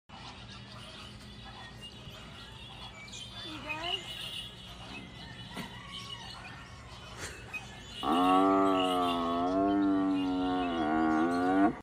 🗣 Our young giraffe Safiri was caught vocalizing again —a rare and awesome sound to hear!
💬👂 Giraffes communicate using low-frequency hums, snorts, grunts, and even infrasonic sounds that humans often can’t hear.